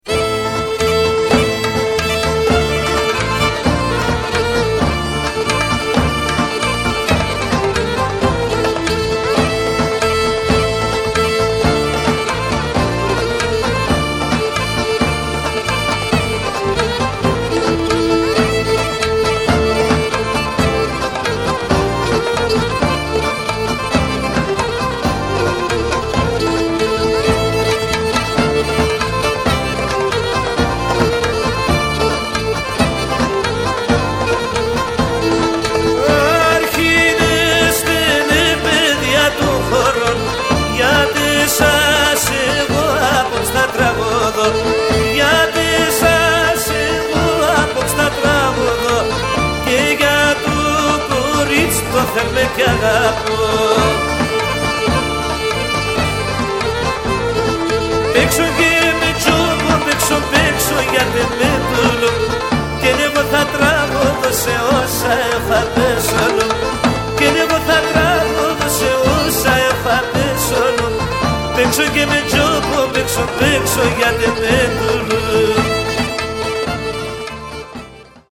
Ποντιακά Παραδοσιακά